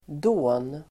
Uttal: [då:n]